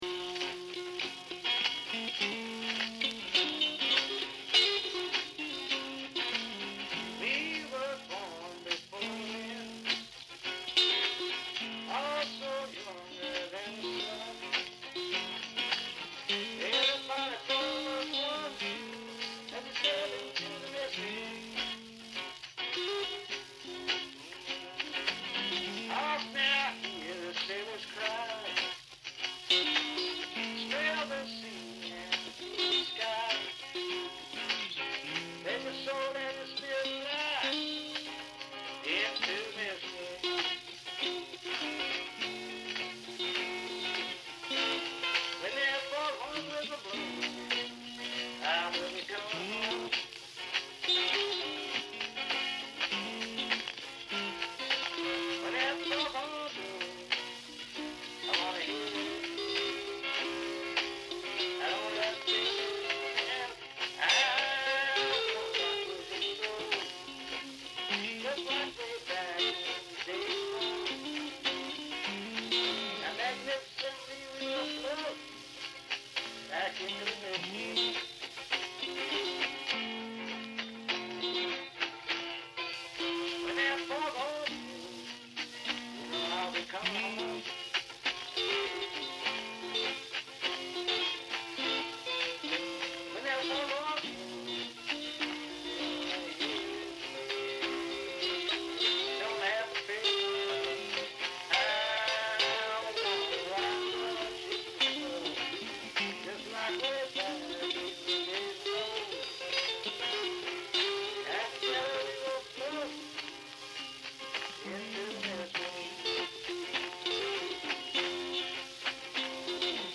for Guitars, Mandolin, Accordion, & Harmonica
Percussion
- From The Original 78 rpm Acetate Records !